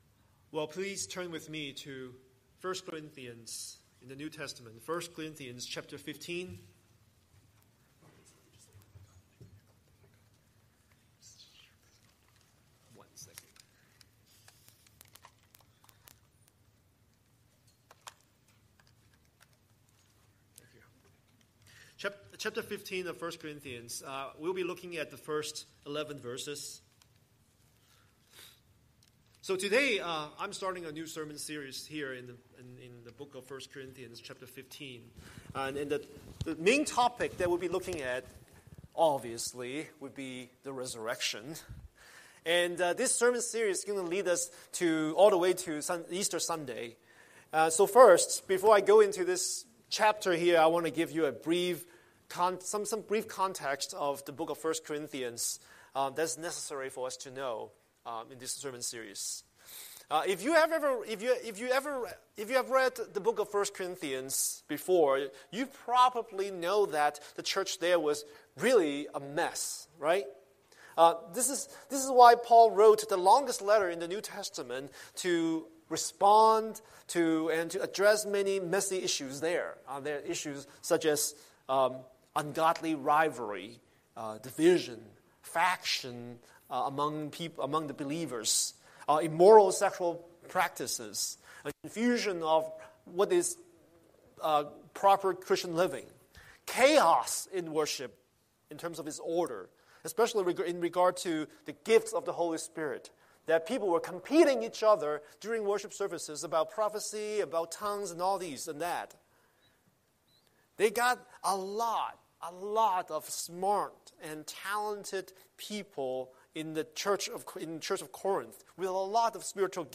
Scripture: 1 Corinthians 15:1–11 Series: Sunday Sermon